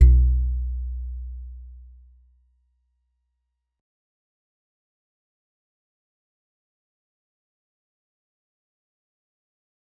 G_Musicbox-B1-mf.wav